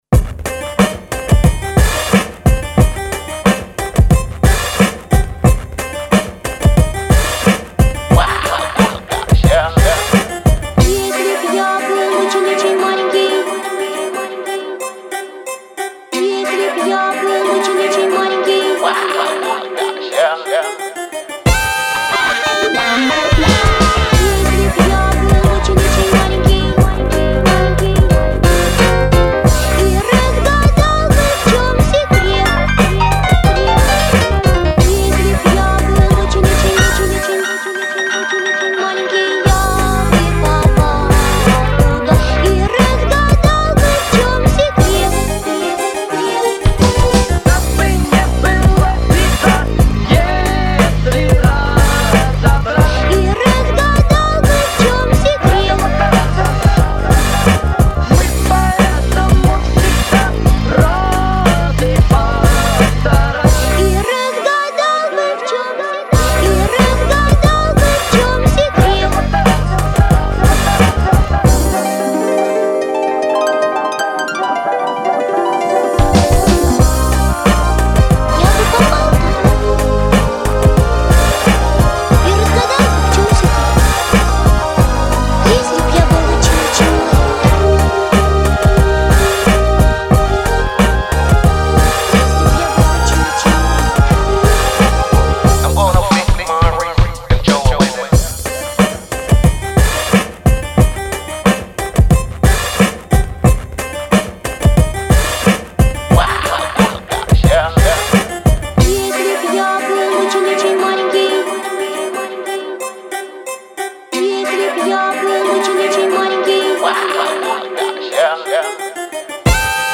• Жанр: Авторская песня
• Жанр: Легкая